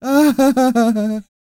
Index of /90_sSampleCDs/ILIO - Vocal Planet VOL-3 - Jazz & FX/Partition I/2 LAUGHS